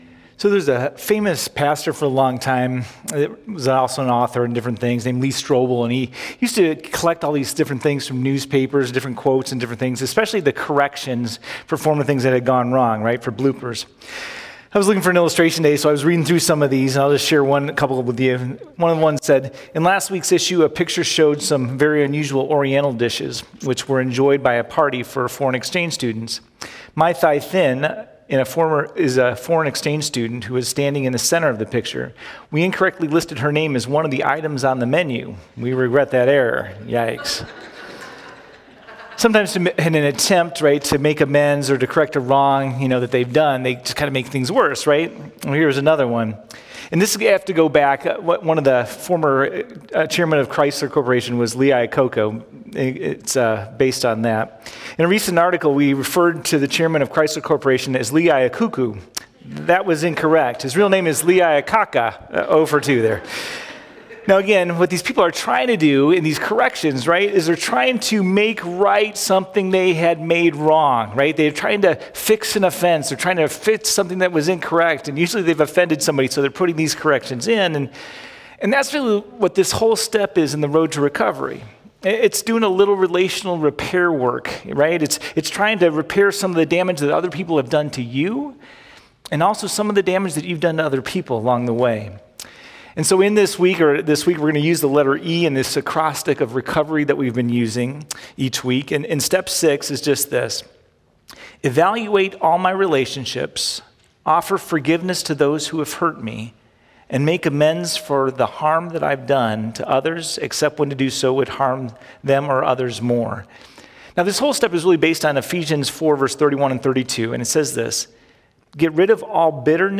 1103-Sermon.mp3